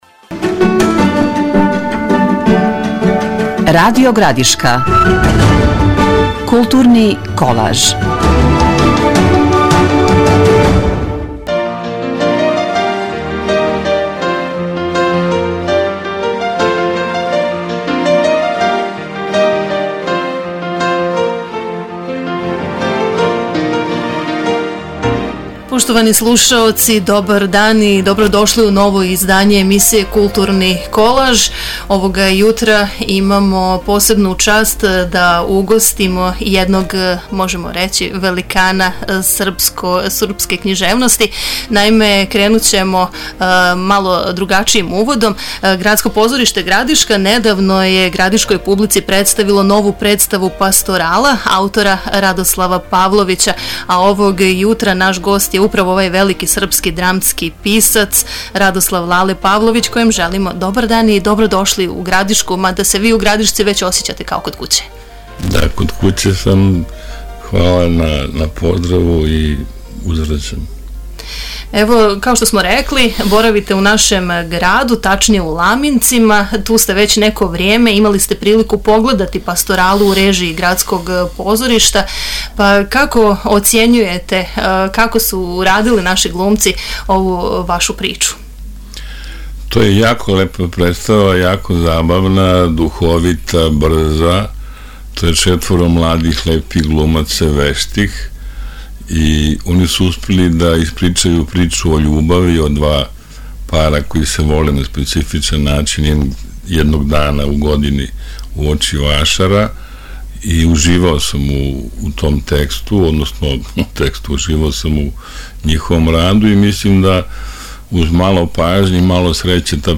Gostujući u emisiji „Kulturni kolaž“ Radio Gradiške ovaj veliki srpski dramski pisac je rekao da prezadovoljan kako su mladi glumci u režiji „Gradskog pozorišta“ odigrali predstavu.